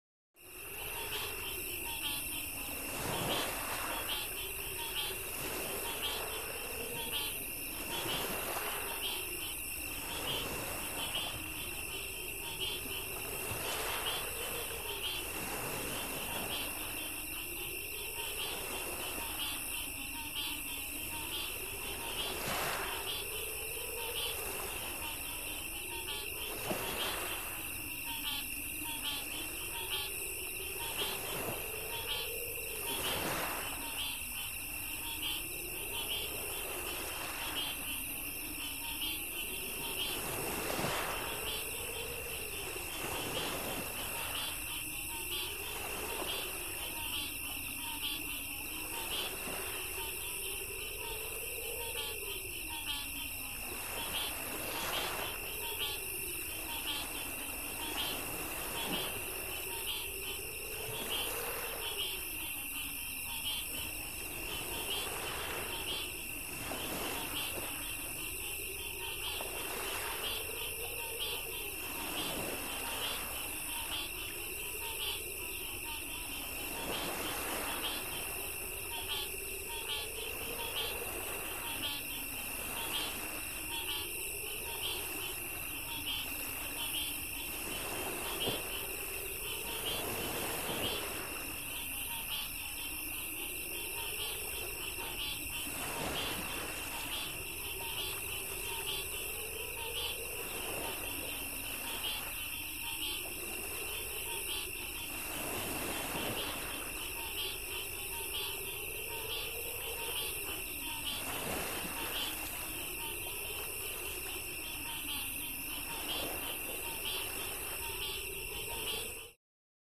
Marshland - Water, Crickets, Wind